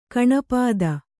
♪ kaṇapāda